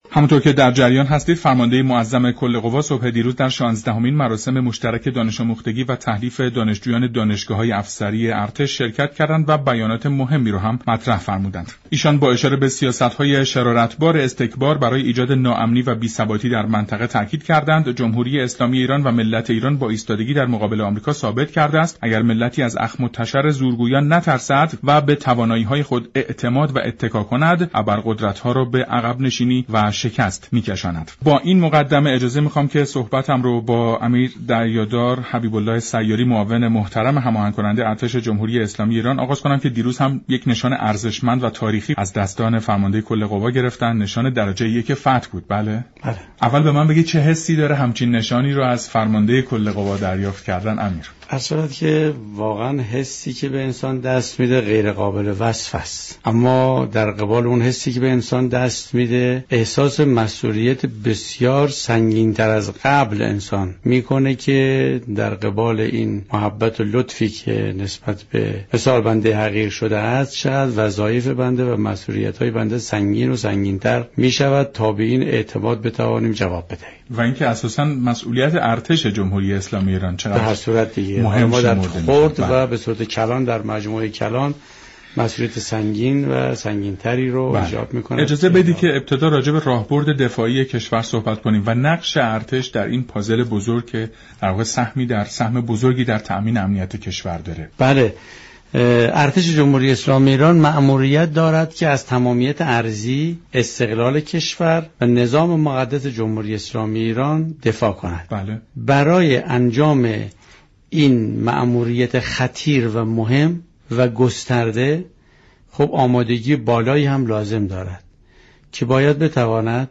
امیر دریادار حبیب الله سیاری در برنامه جهان سیاست رادیو ایران گفت